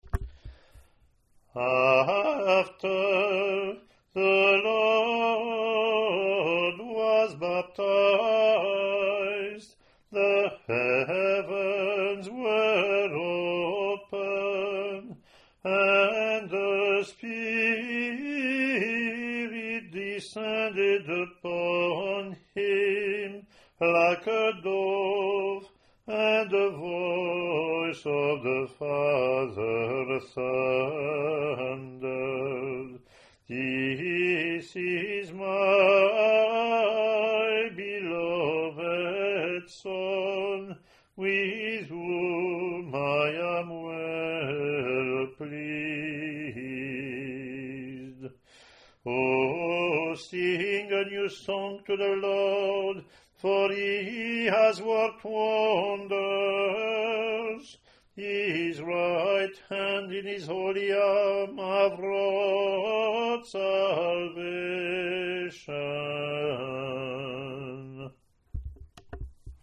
English antiphon – English verseLatin antiphon+verses)
otbl-introit-eng-pw.mp3